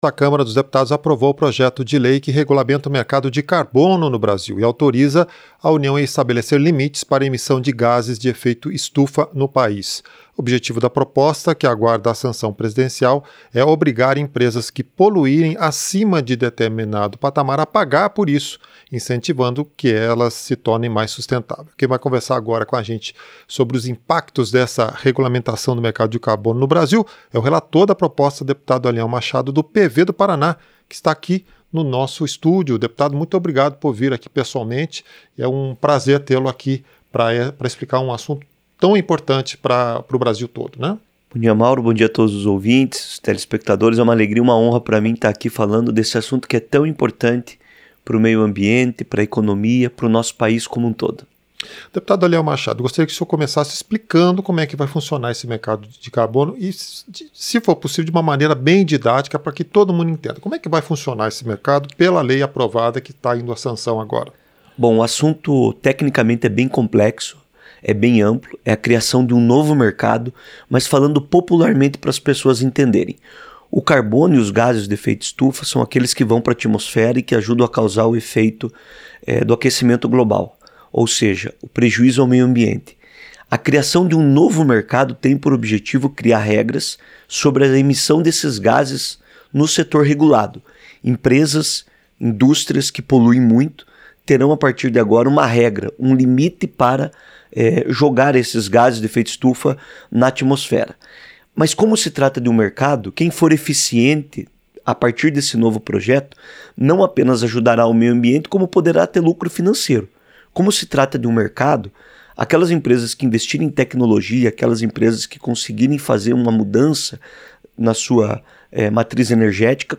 Entrevista - Dep. Aliel Machado (PV-PR)